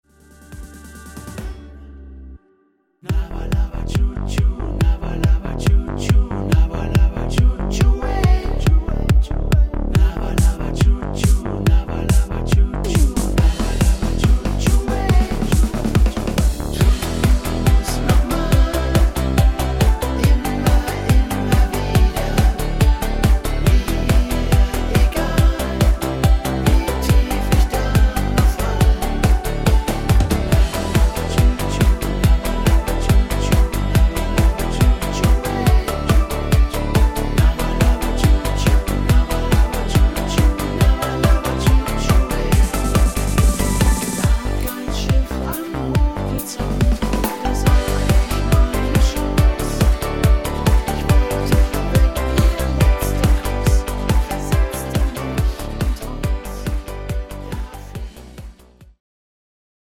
starker Stimmungs Song